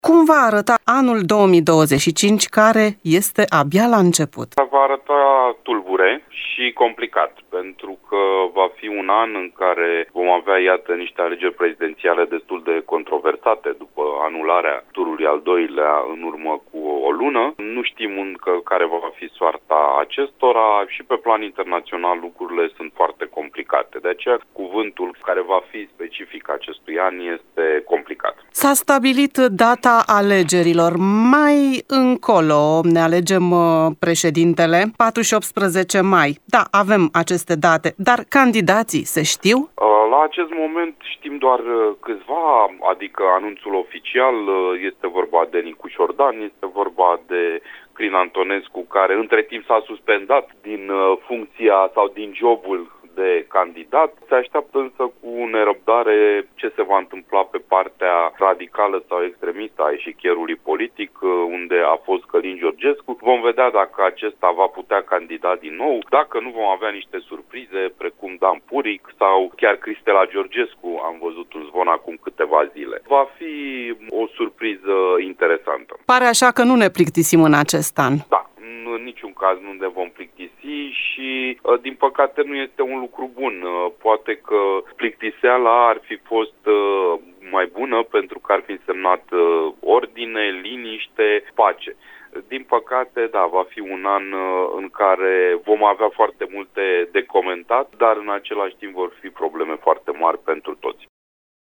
dezbate